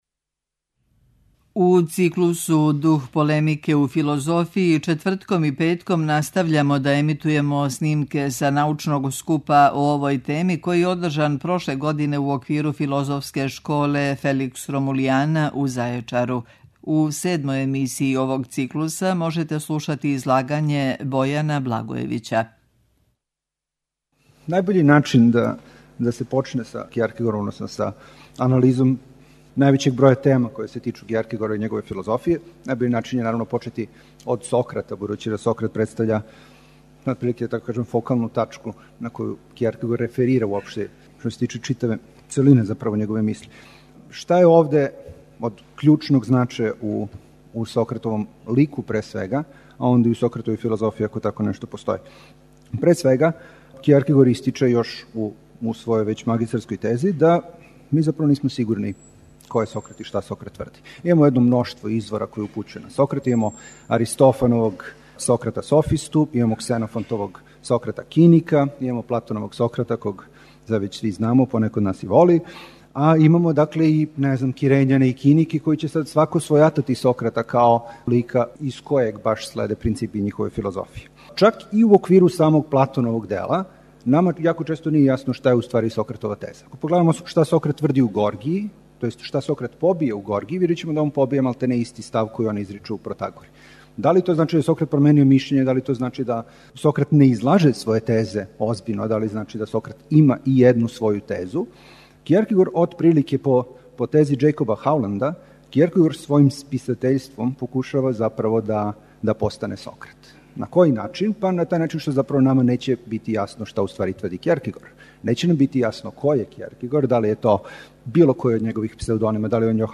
У циклусу ДУХ ПОЛЕМИКЕ У ФИЛОЗОФИЈИ четвртком и петком емитујемо снимке са научног скупа о овој теми, који је одржан прошле године у оквиру Филозофске школе Феликс Ромулиана у Зајечару.
Научни скупови